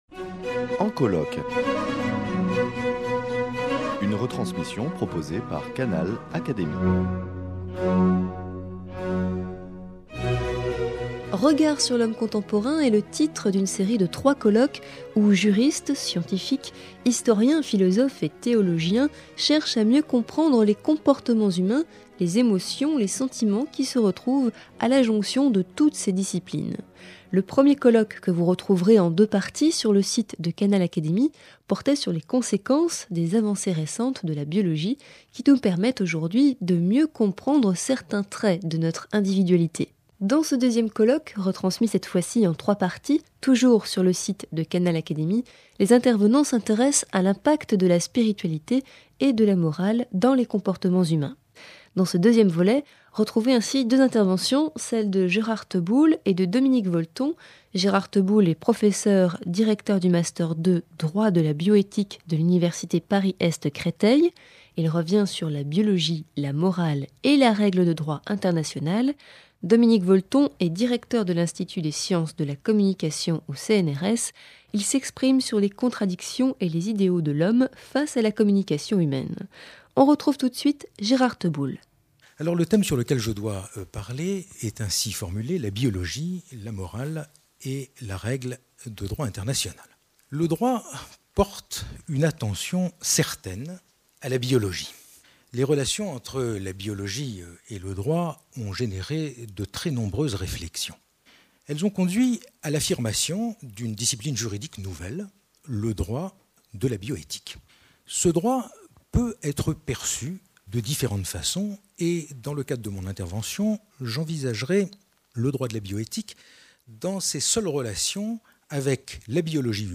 Canal Académie retransmet ici le deuxième colloque en trois émissions.